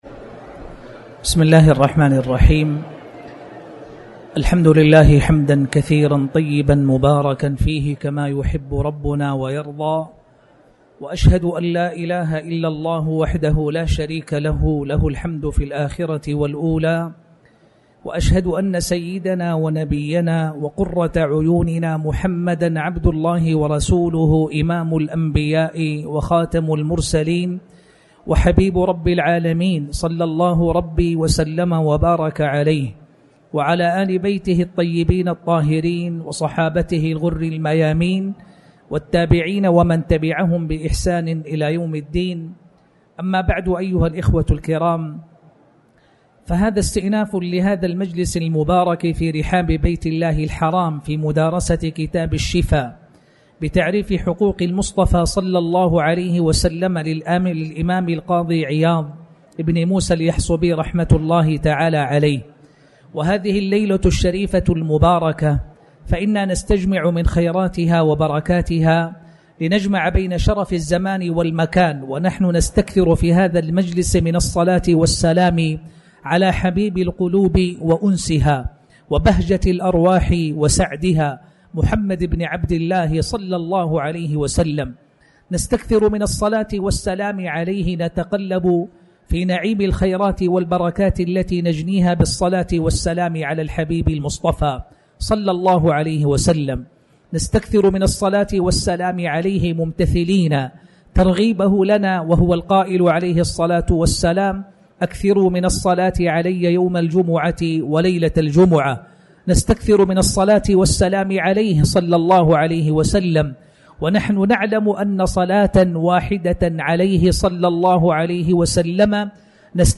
تاريخ النشر ٦ ذو القعدة ١٤٣٩ هـ المكان: المسجد الحرام الشيخ